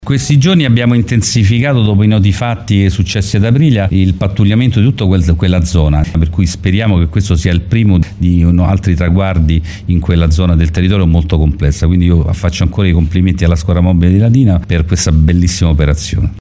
Soddisfazione è stata espressa dal questore di Latina Fausto Vinci: “Speriamo che questa operazione sia solo il primo di altri traguardi in quella sono del territorio molto complessa”.